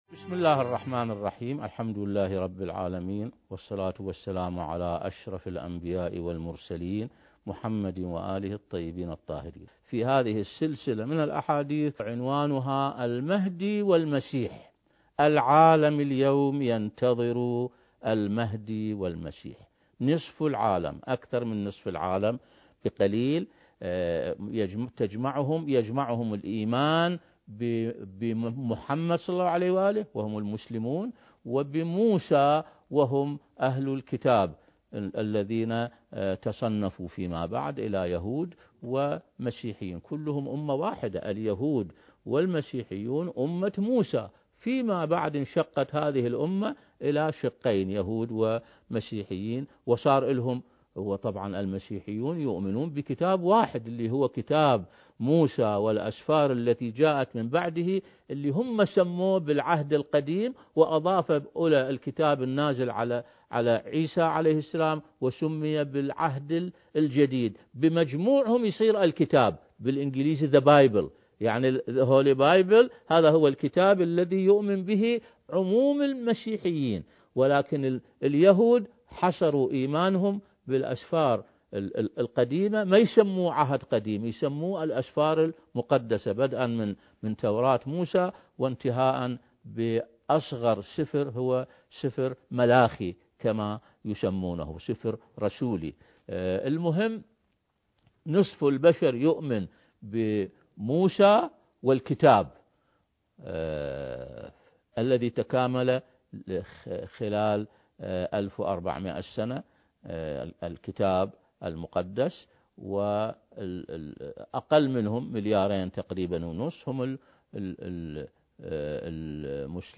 حديث بمناسبة ولادة الإمام المهدي (عجّل الله فرجه) (4) المكان: مركز فجر عاشوراء الثقافي - العتبة الحسينية المقدسة التاريخ: 1441 للهجرة